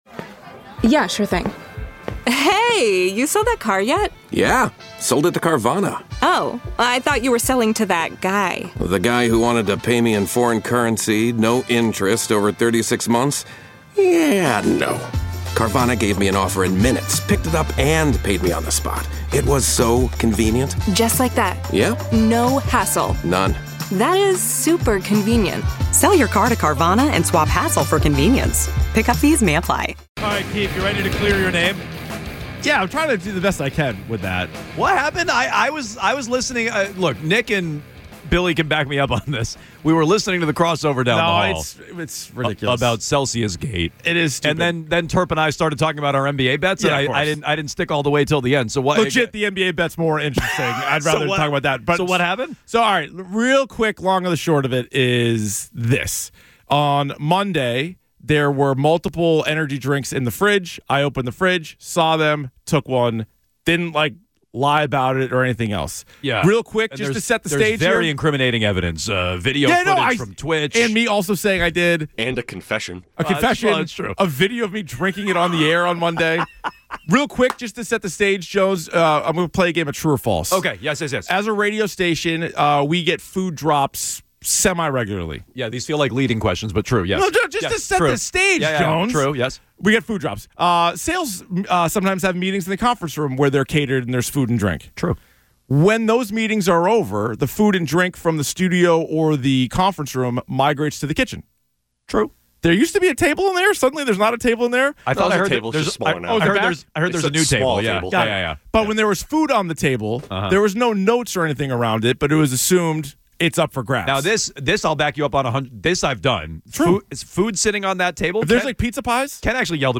airs live weekdays 10 a.m. - 2 p.m. on WEEI-FM (the home of the Red Sox) in Boston and across the WEEI network in New England.
Sports